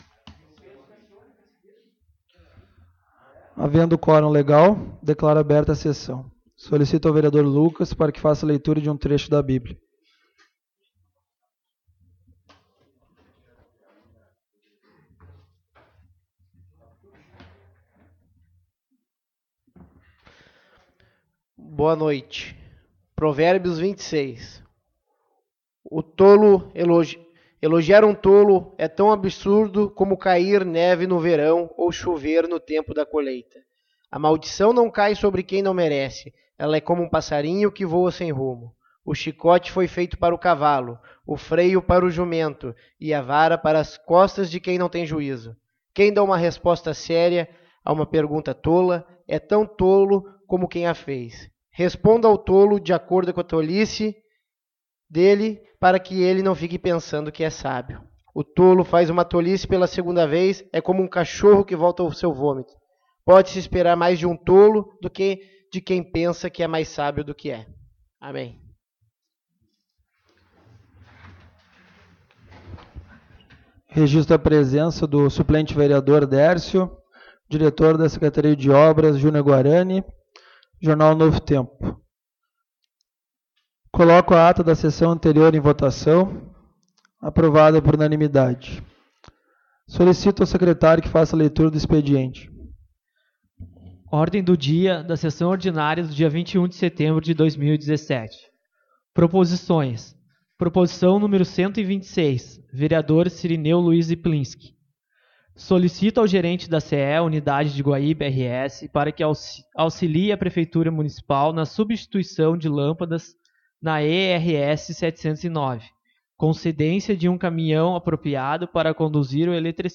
Publicação: 24/02/2021 às 11:06 Abertura: 24/02/2021 às 11:06 Ano base: 2017 Número: Palavras-chave: Anexos da publicação Áudio da Sessão Ordinária de 21.09.2017 às 19h. 24/02/2021 11:06 Compartilhar essa página...